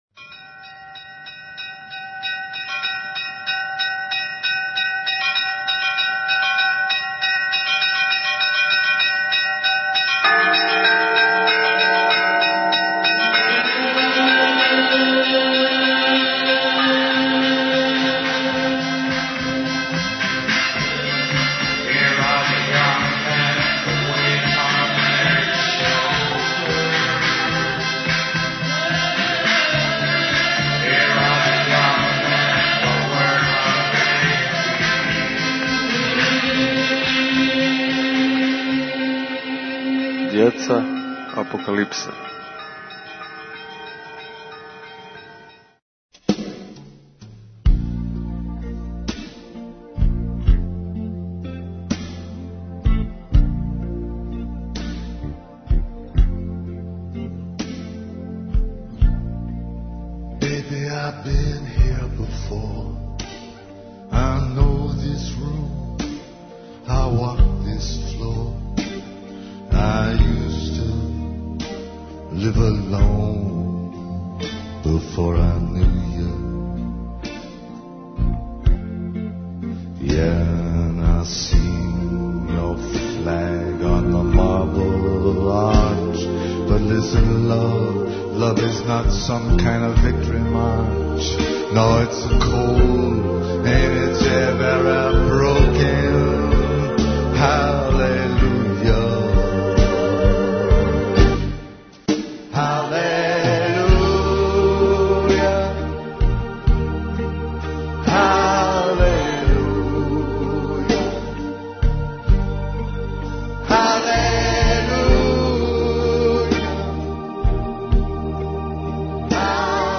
Запис са донаторске вечери за обнову цркве Светог Преображења Господњег и подизање парохијског дома на Жабљаку, које је, са благословом Преосвећене Господе Епископа Бачког Иринеја и Будимљанско - никшићког Јоаникија, одржано 17. октобра у позоришту младих у Новом Саду.
гуслари